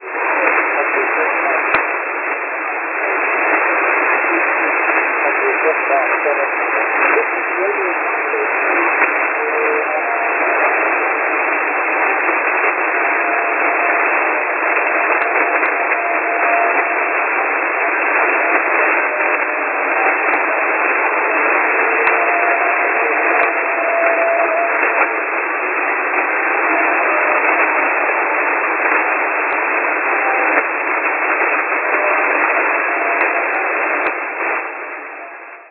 Last night I was awake late night and first time I heard weak US-pirate station on 6925 KHz!!
Signal was USB and very weak, but luckily I got identification; "This is Radio ......"
Here is a short clip of this very weak ID (9th May 2008 at 23.29utc).